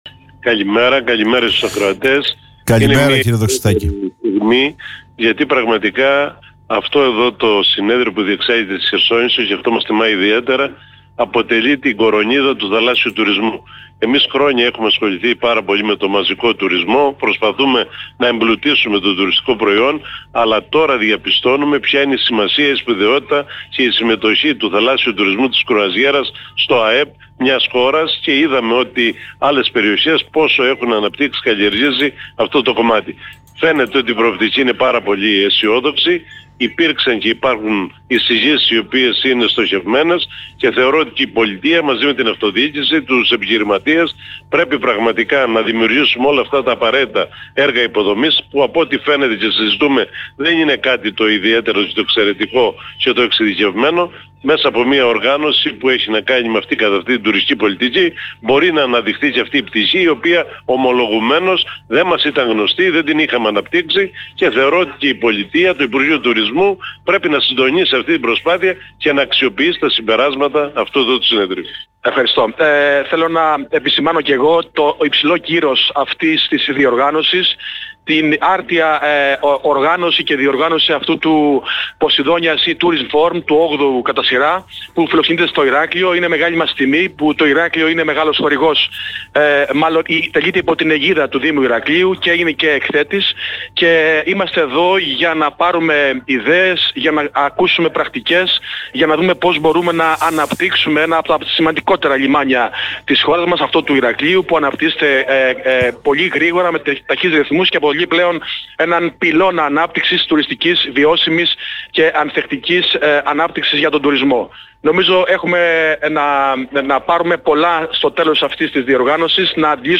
τον Δήμαρχο Χερσονήσου, Ζαχαρία Δοξαστάκη και τον Αντιδήμαρχο Ηρακλείου, Γιώργο Αγριμανάκη, μίλησαν για τη σημασία διεξαγωγής του φόρουμ.